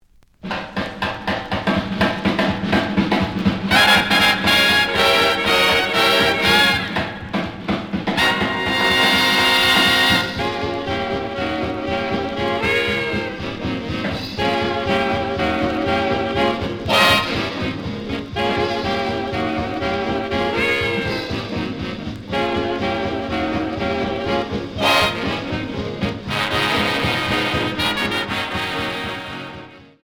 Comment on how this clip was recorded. The audio sample is recorded from the actual item. ●Format: 7 inch